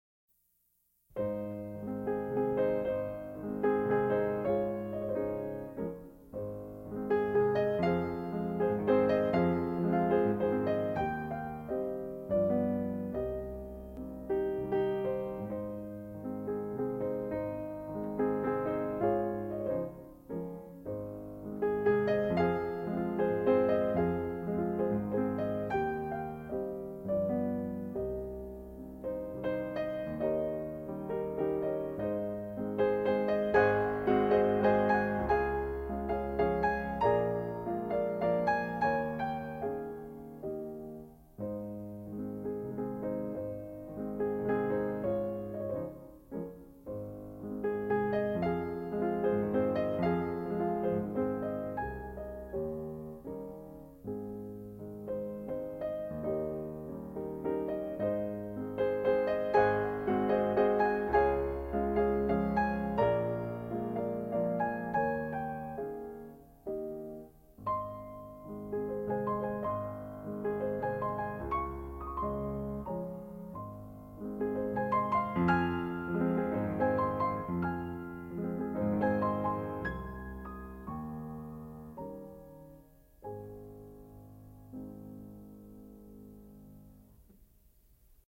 for piano, four hands
in A-flat major
Category:Classical music